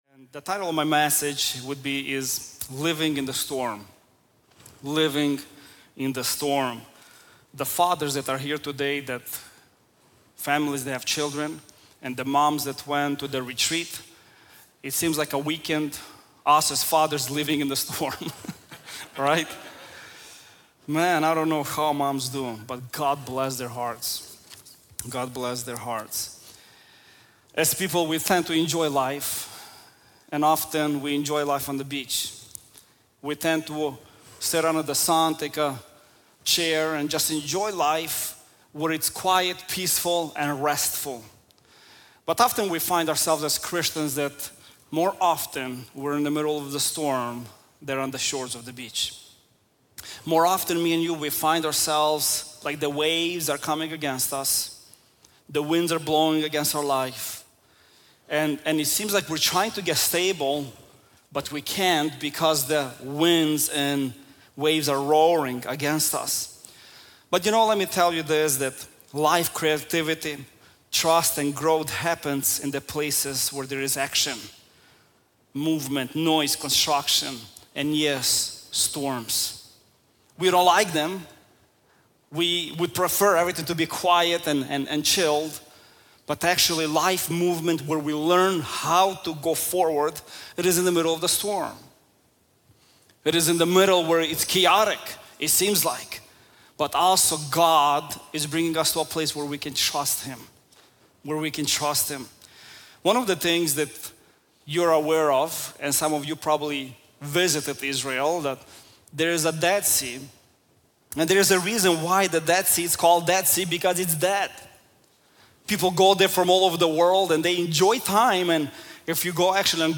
Living In The Storm | Times Square Church Sermons